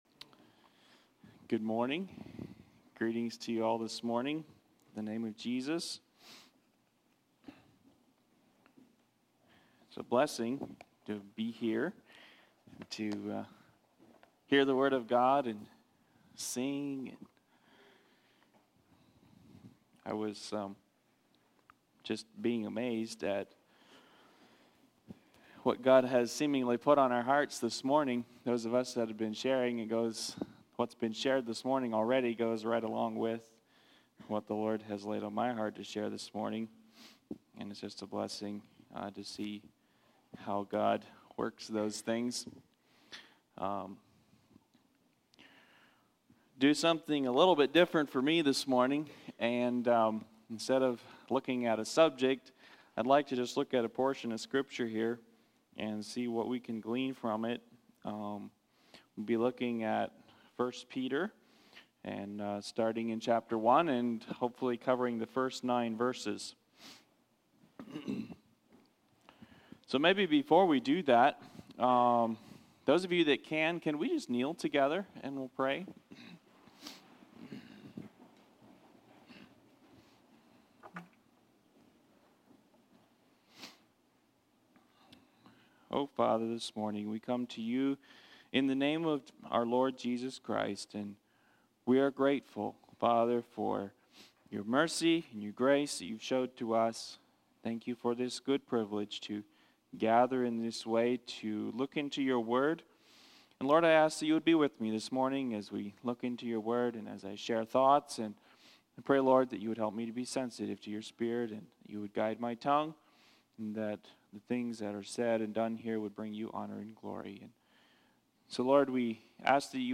Expository Sermon from I Peter 1